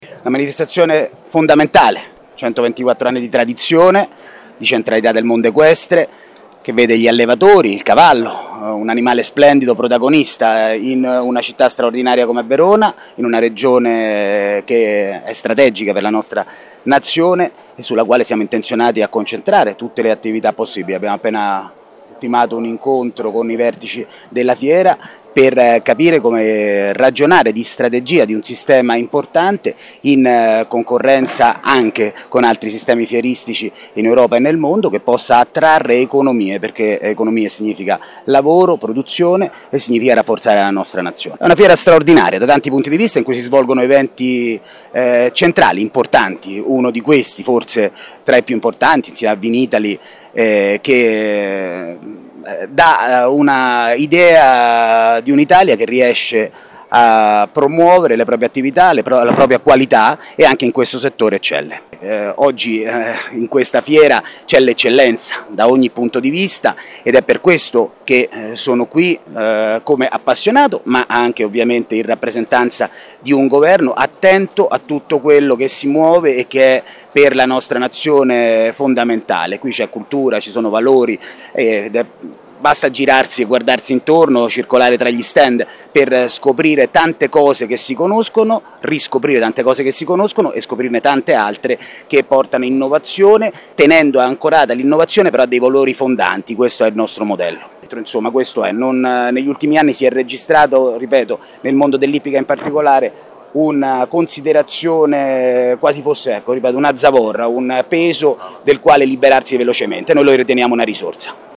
Francesco Lollobrigida, Ministro delle politiche agricole e della sovranità alimentare:
Francesco-Lollobrigida-Ministro-delle-politiche-agricole-e-della-sovranità-alimentare-alla-FIracavalli-2022.mp3